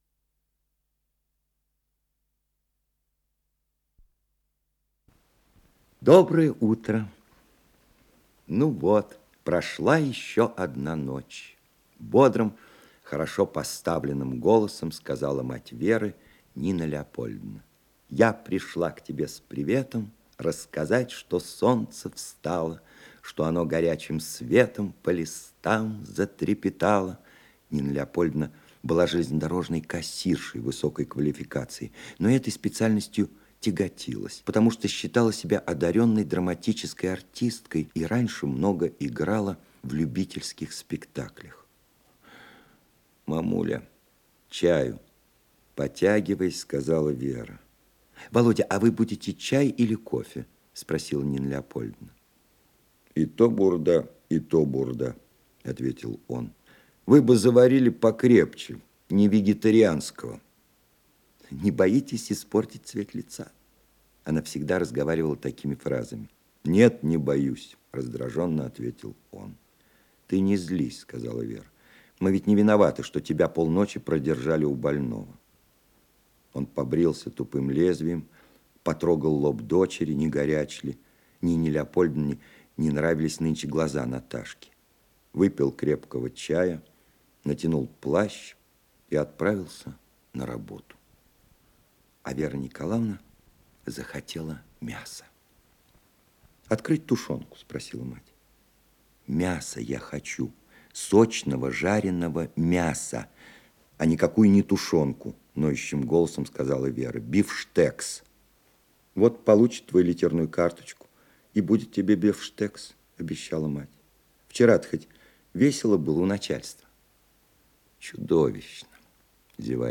Исполнитель: Алексей Баталов - чтение